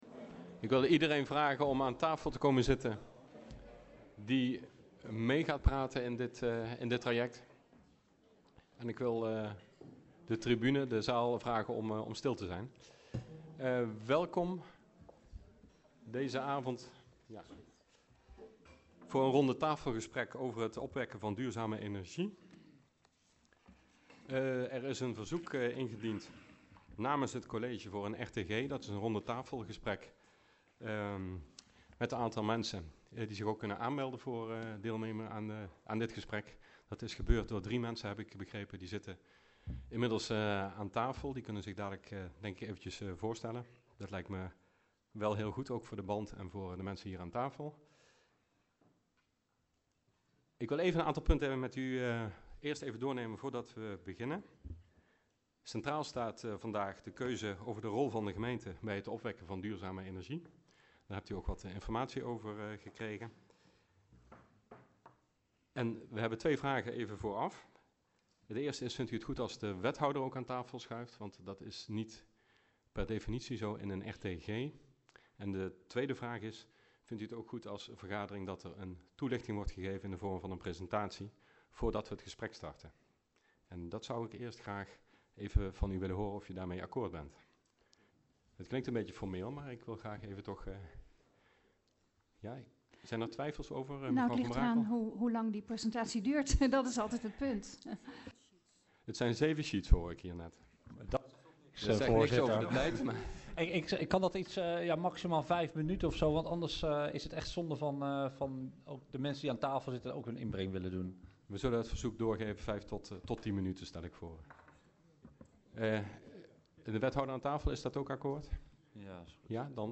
Locatie VMBO Het Westeraam Elst Voorzitter dhr. H. van den Moosdijk Toelichting Rondetafelgesprek Discussienota positionering gemeente Overbetuwe bij opwekken duurzame energie Agenda documenten 19-09-03 Opname 1.